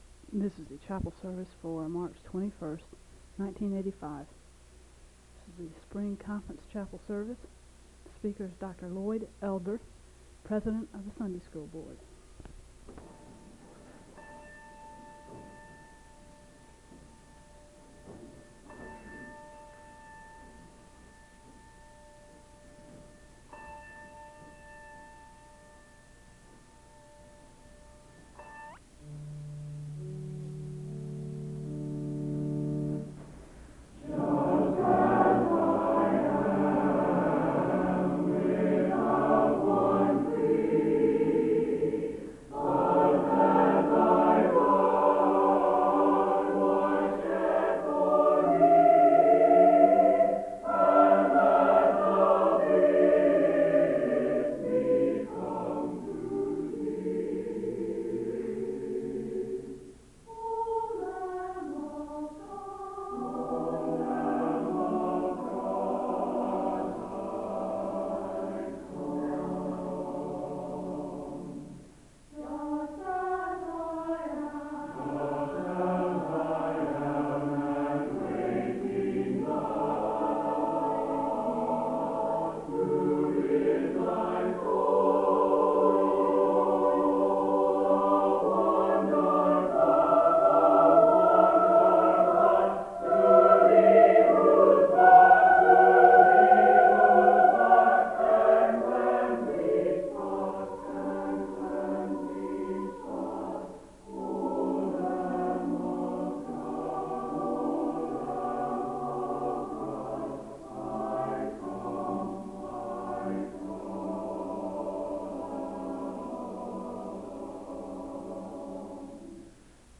The service begins with a song performed by the choir and a moment of prayer (0:00-3:45). A welcome is offered to the congregation, and there is a responsive reading (3:46-5:30).
The choir sings a song of worship (9:29-12:48).
Location Wake Forest (N.C.)